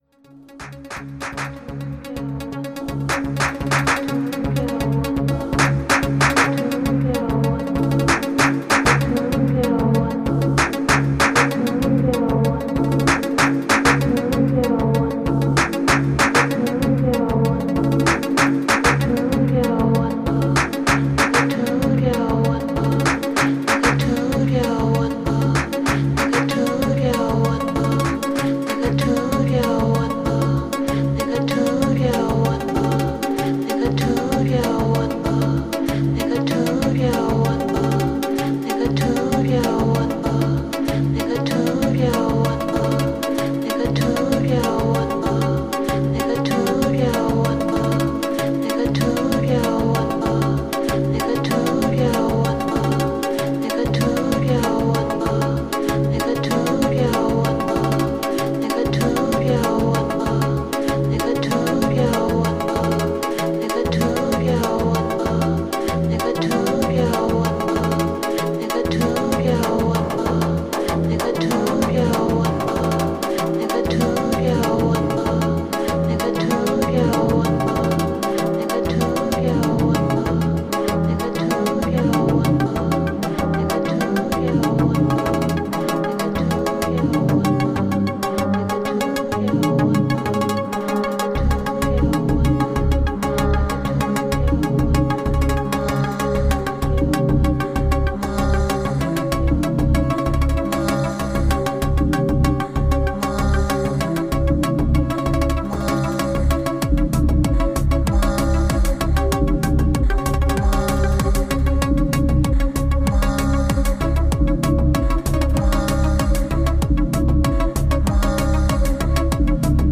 Segments of a live improvisation (modx) on a DJ session by my partner.